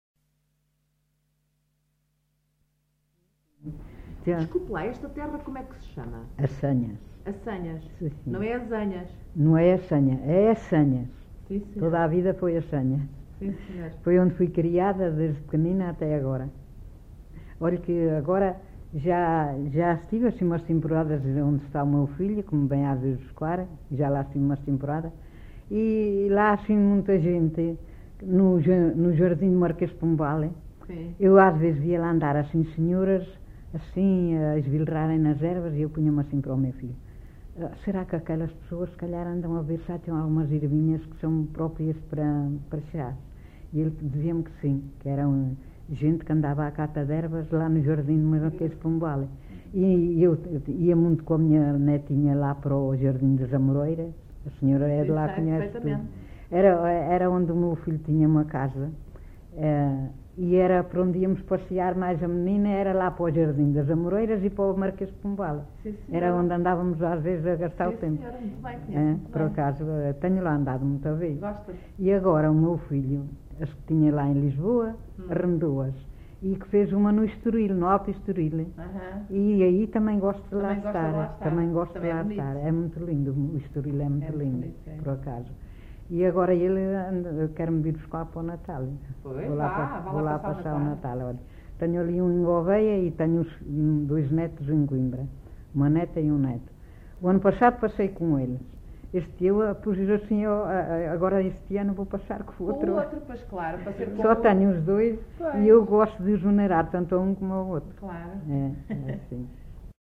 Assanhas (Figueiró da Serra), excerto 7
LocalidadeAssanhas (Celorico da Beira, Guarda)